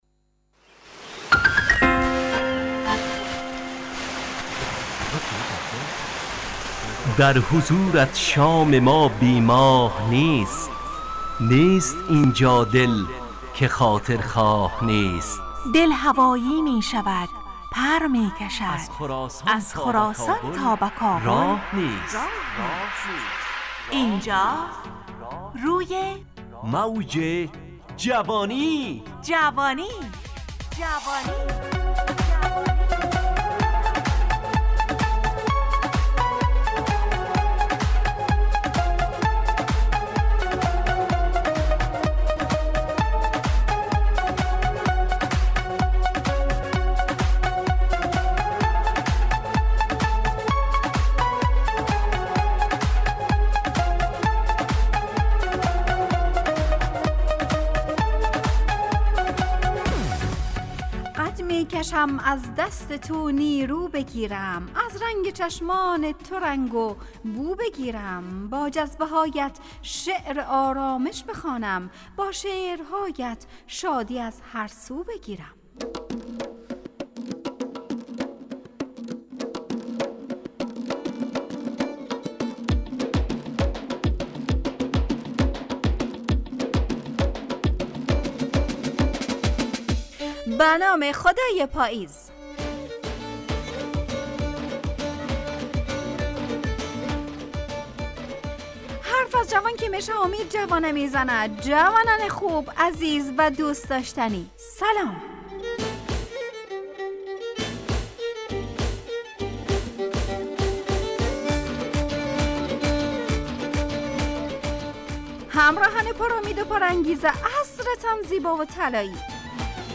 روی موج جوانی، برنامه شادو عصرانه رادیودری.
همراه با ترانه و موسیقی مدت برنامه 55 دقیقه . بحث محوری این هفته (امید) تهیه کننده